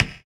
SOUTHSIDE_percussion_basic_percussion.wav